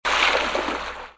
snd_splash.mp3